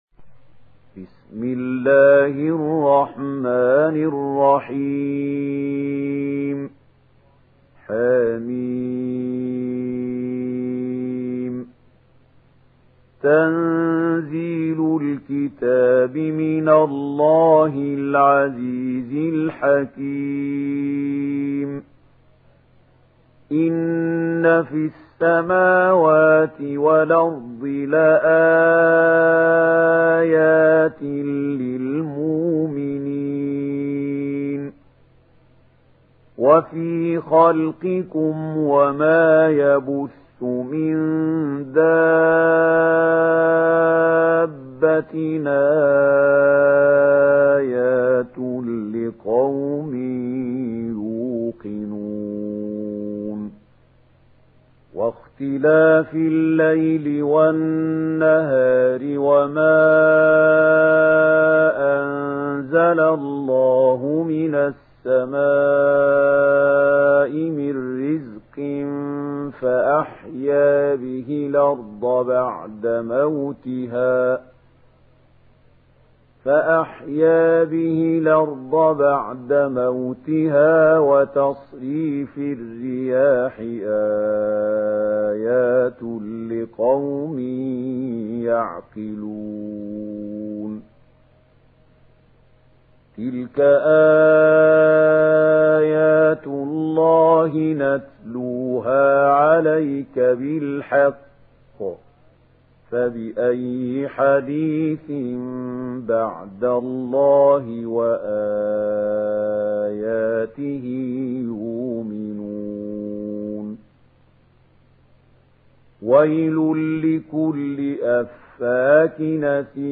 Surat Al Jathiyah Download mp3 Mahmoud Khalil Al Hussary Riwayat Warsh dari Nafi, Download Quran dan mendengarkan mp3 tautan langsung penuh